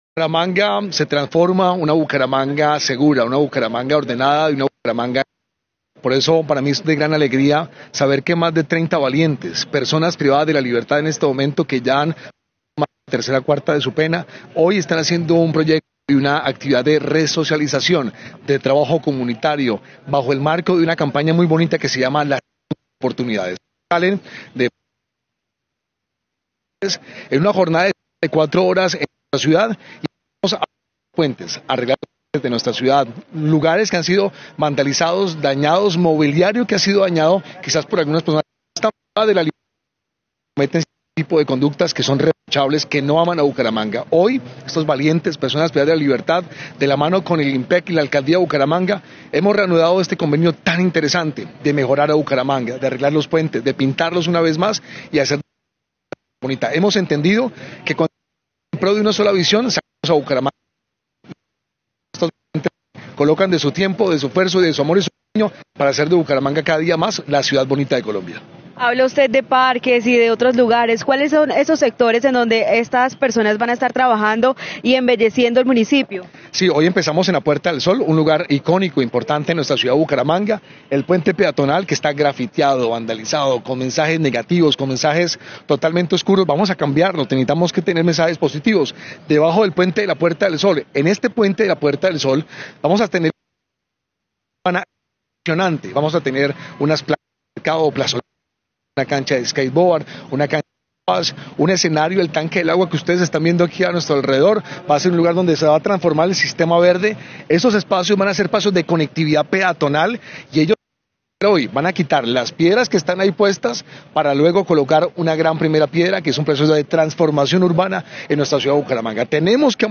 Cristian Portilla, alcalde de Bucaramanga